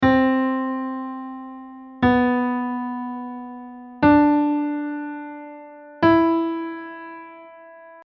Transcription Example
Of course, in our example, it could be anything rhythmically, since there are only four notes.
If you came up with the notes “C”, “B”, “D” and “E”, you heard everything correctly.